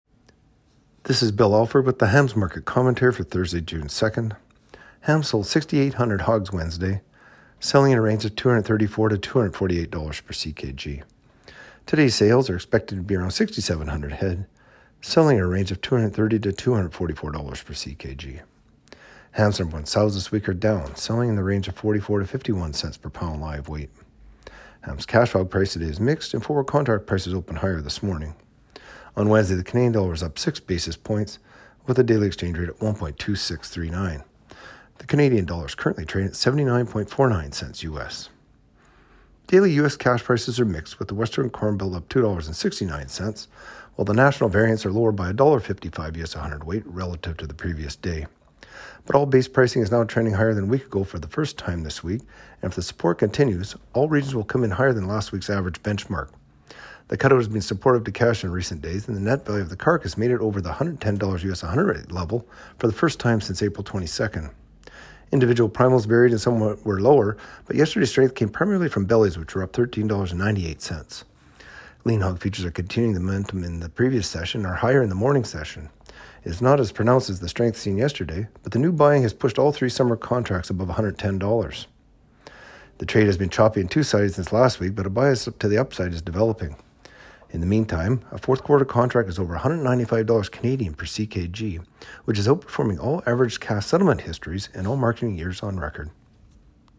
Market-Commentary-Jun-2-22.mp3